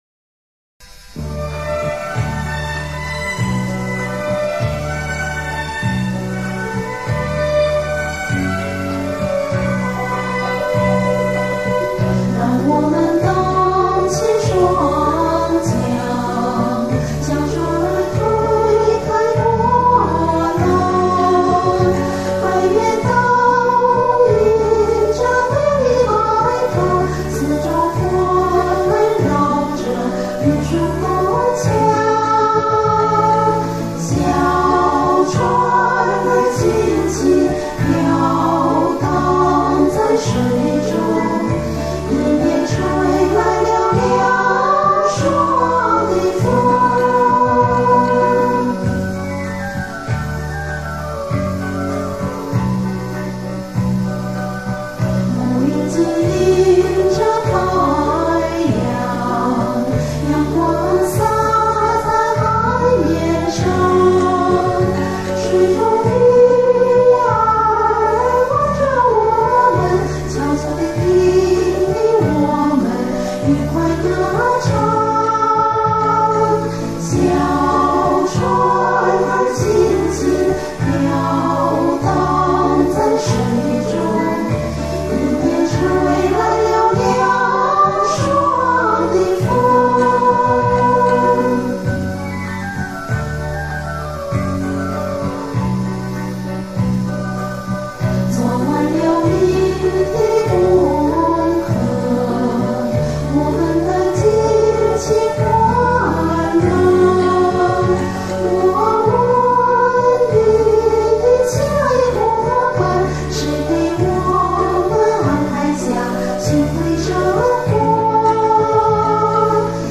这歌是我当初学和声录音时随便录的，录完就和伴奏一起混合下载了，噪音什么的也都在里面，那时也不懂要降噪。
由于是录完一轨接着录第二轨、三轨、四轨......，电流声也很大，还用的是耳麦，为了防止喷麦，还把耳麦上的小麦克风直接对准了眉心，录音效果如何也顾不上。
《让我们荡起双桨》乔羽 词  刘炽 曲